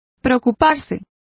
Complete with pronunciation of the translation of worry.